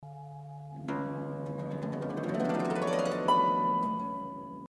Klingelton 2 (Harfenglissando)